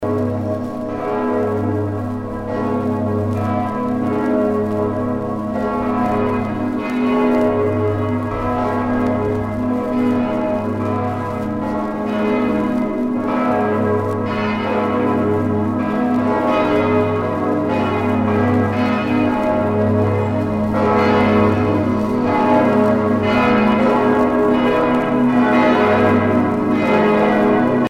La grande volée
Les cloches de la cathédrale de Strasbourg